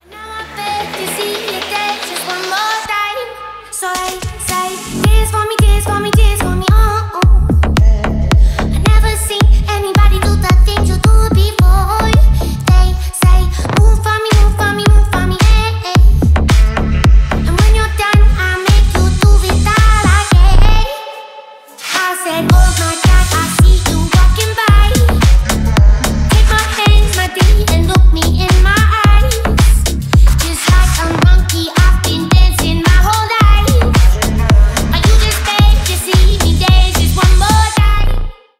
зарубежные клубные